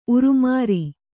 ♪ urumāri